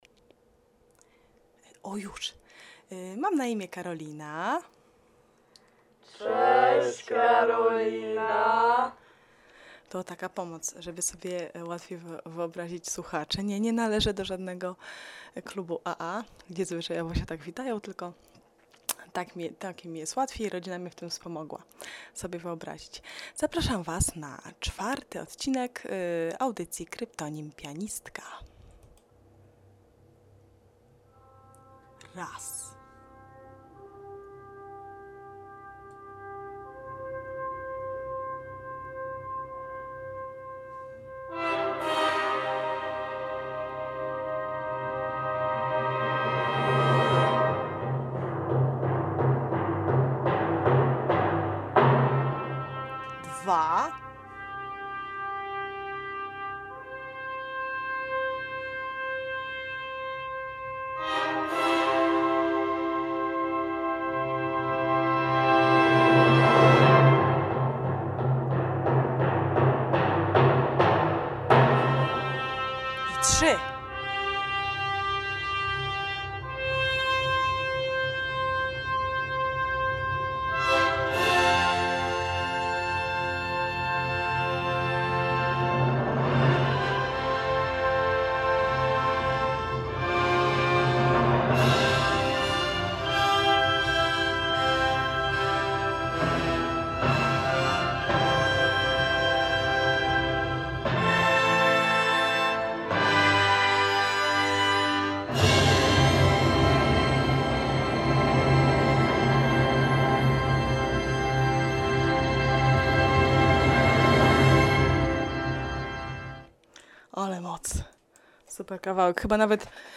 Mimo, że wciąż "cmokam w mikrofon" to opowiadam, co łączy królową, dziadka, łabędzia i Zaratustrę.